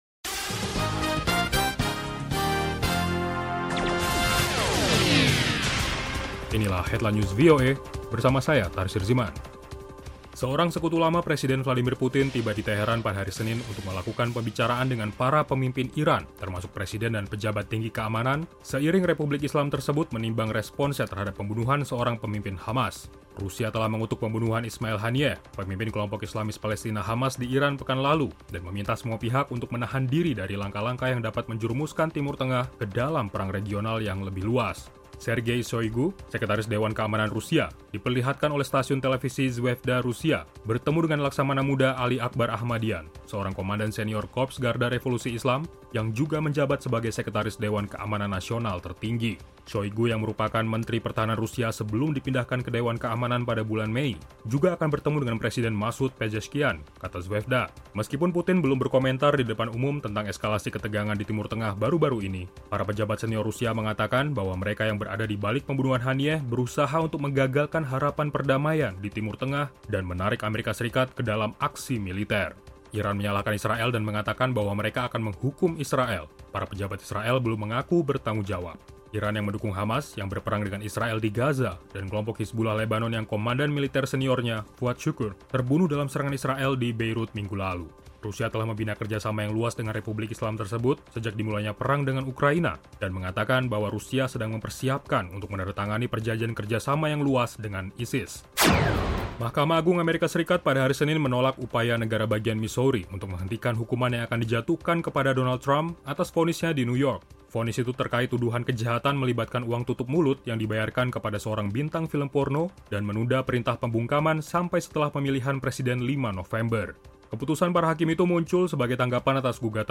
VOA Headline News